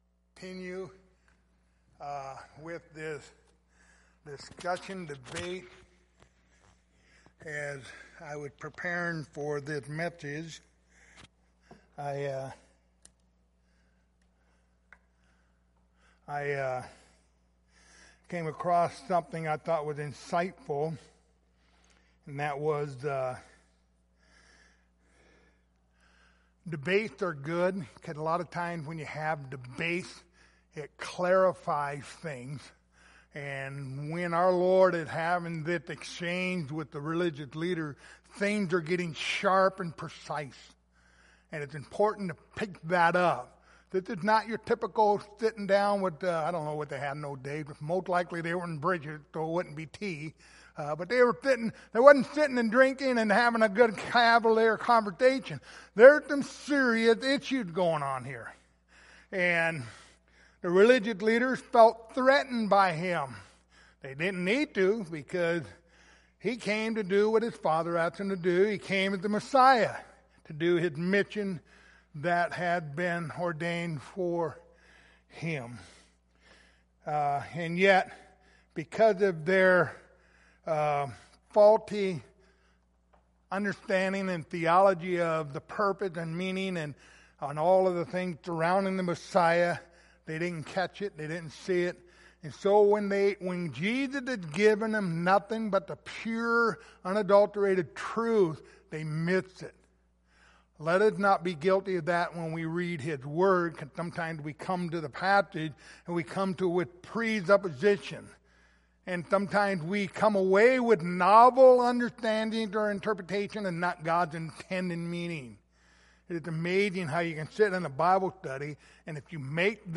Passage: John 8:30-36 Service Type: Wednesday Evening